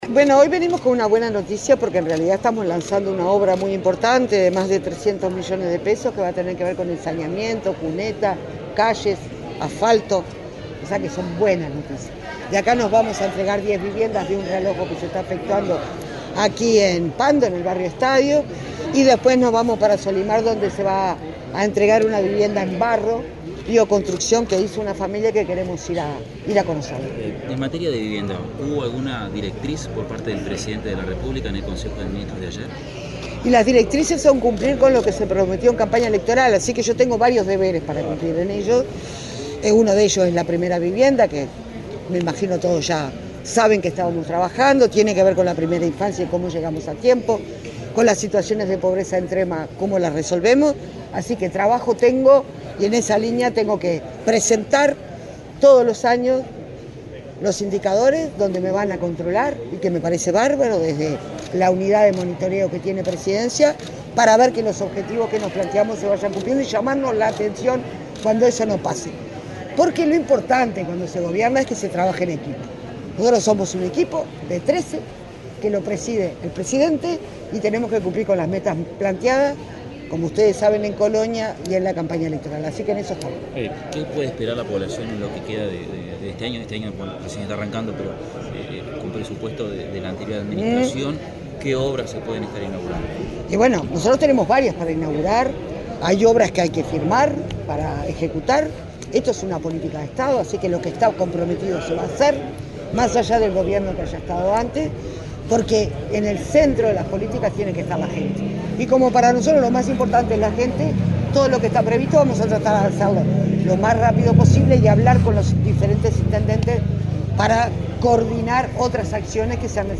Declaraciones de la ministra de Vivienda, Cecilia Cairo
Este miércoles 26, la titular del Ministerio de Vivienda y Ordenamiento Territorial, Cecilia Cairo, dialogó con la prensa, durante una recorrida por Canelones, donde participó en el lanzamiento de obras de infraestructura en Pando Norte y el acto de entrega de 10 viviendas ejecutadas en conjunto por la referida cartera y la Intendencia de Canelones.